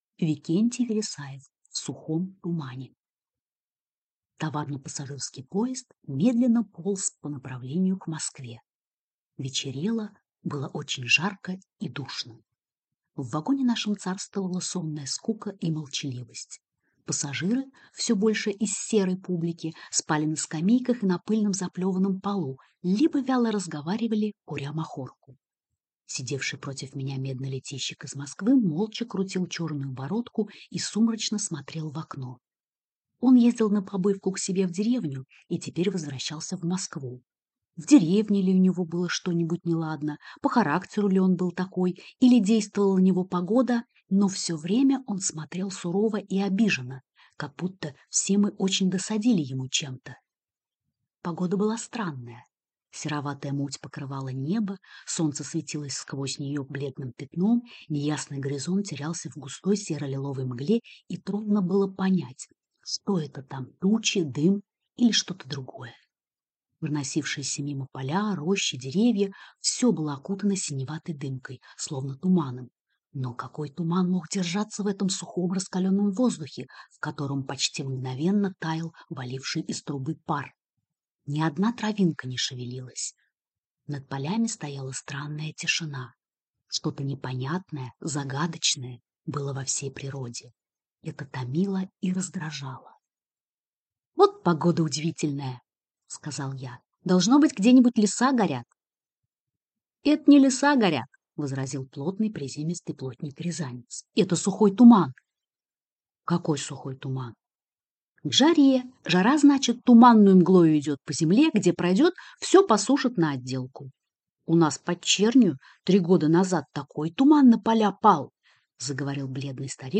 Аудиокнига В сухом тумане | Библиотека аудиокниг